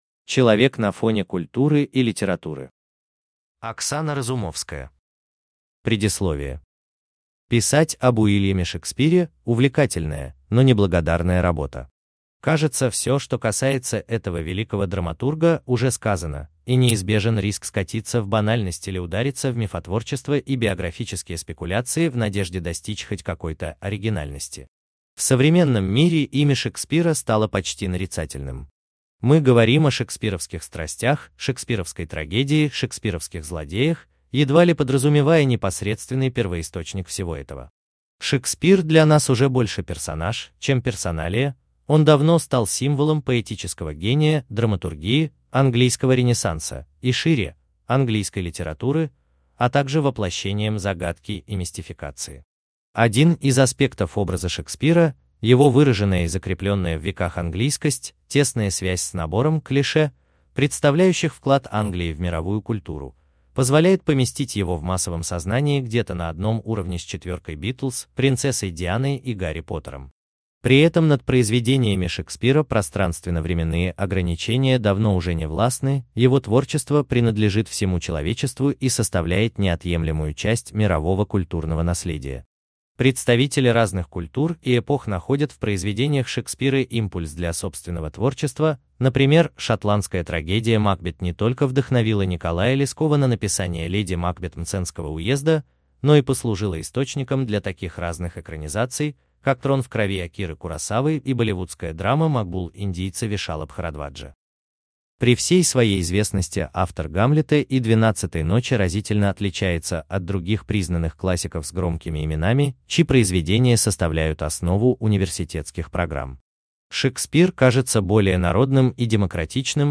Аудиокнига Уильям Шекспир. Человек на фоне культуры и литературы | Библиотека аудиокниг
Читает аудиокнигу Искусственный интеллект